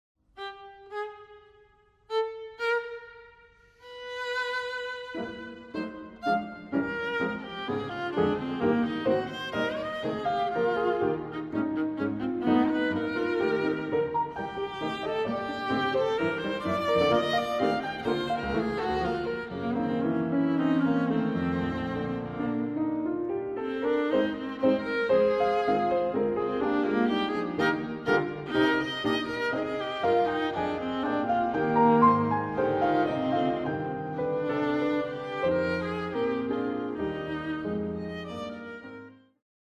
Suite for viola and piano op. 102a (World Premiere)